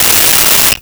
Cell Phone Ring 09
Cell Phone Ring 09.wav